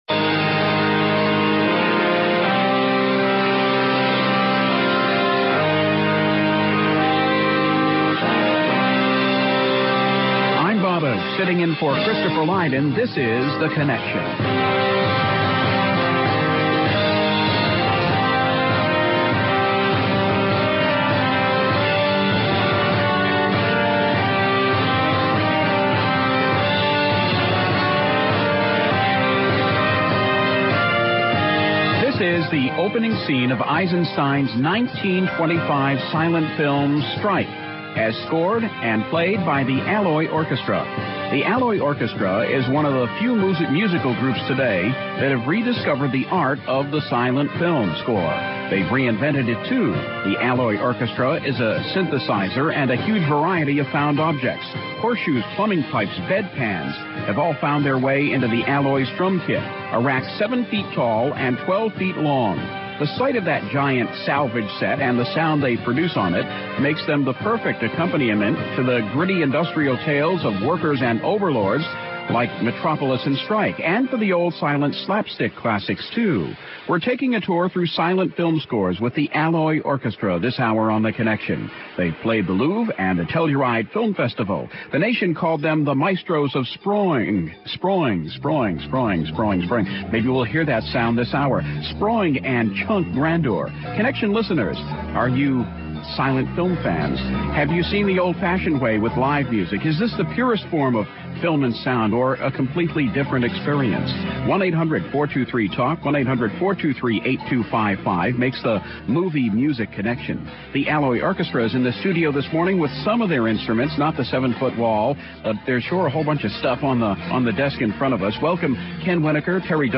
The Alloy Orchestra is just a trio and most of what they play is junk.
They play bedpans and dentist trays and galvanized metal ducts. They play plumbing-pipe chimes and two-by-four vibes and horseshoes for triangles.
Sure, they play the synthesizer, too, and sometimes an accordion or the rare clarinet, but mostly they pound out rhythms on a seven-foot rack of found objects.